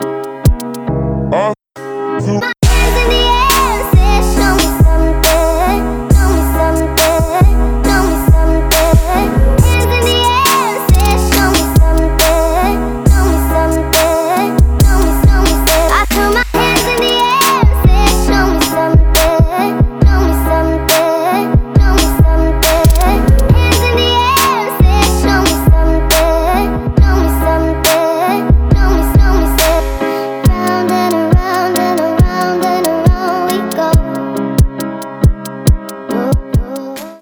Ремикс
Танцевальные